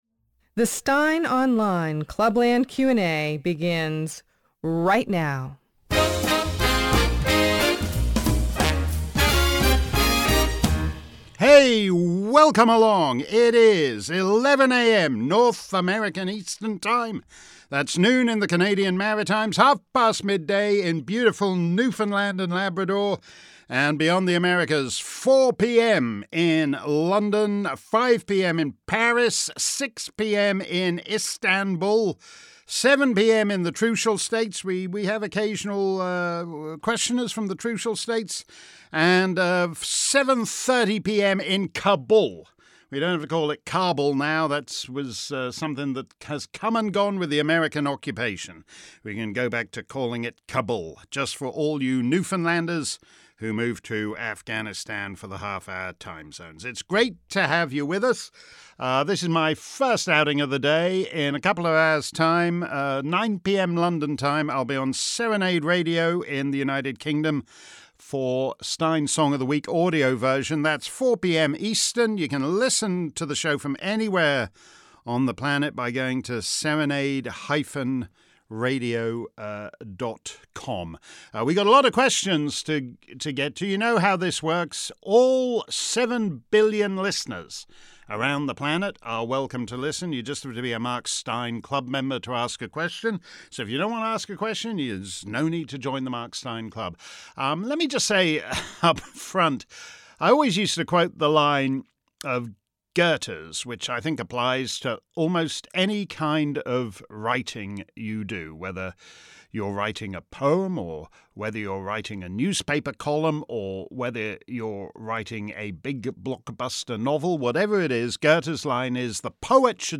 If you missed today's edition of our Clubland Q&A live around the planet, here's the action replay.